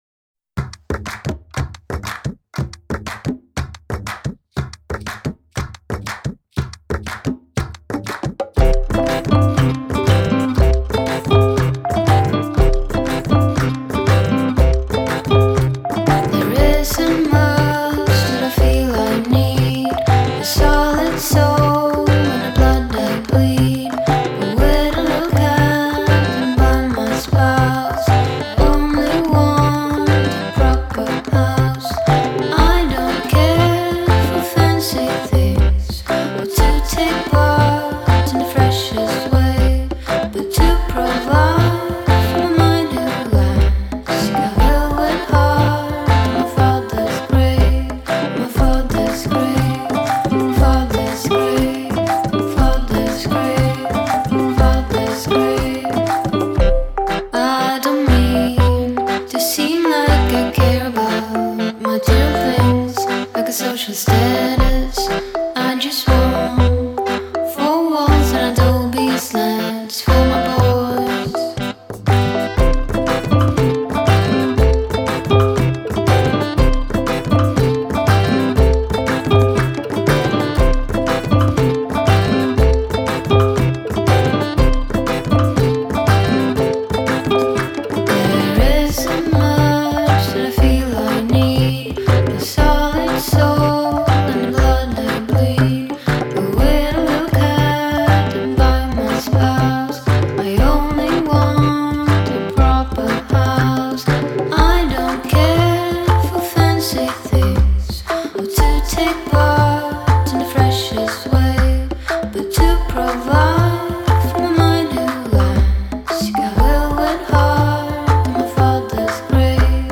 sounding even better with a Jamaican island influence